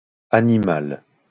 wymowa:
IPA[aniˈmal] ?/i; lm [aniˈmo]